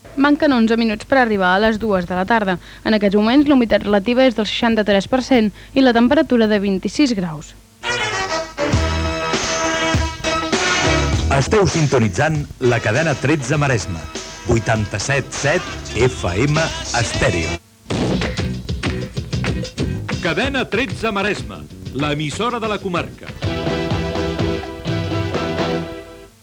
98da8442a6deb072ad3393fdd2f4848e5a03fd66.mp3 Títol Cadena 13 Maresme Emissora Ràdio El Masnou Maresme Cadena Cadena 13 Titularitat Privada nacional Descripció Hora, temperatura i indicatiu.